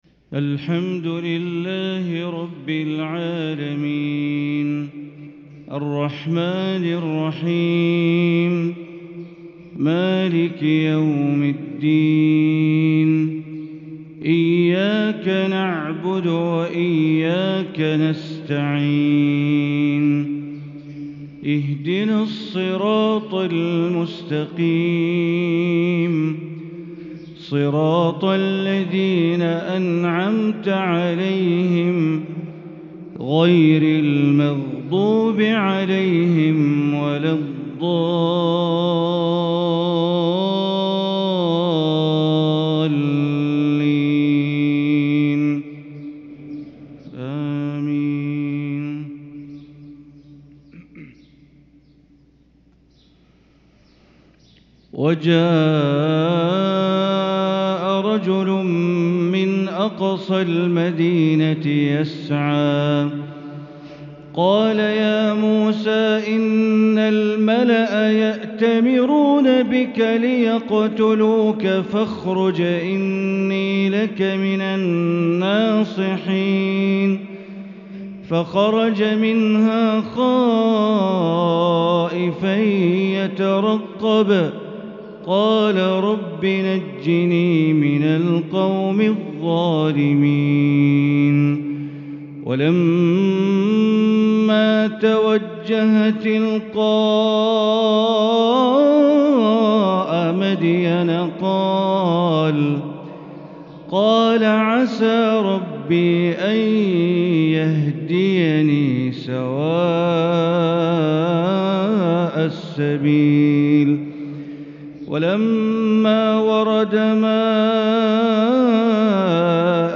فجر الأثنين 9-6-1444هـ من سورة القصص 20-37 | Fajr prayer from Surat Al-Qasas 2-1-2023 > 1444 🕋 > الفروض - تلاوات الحرمين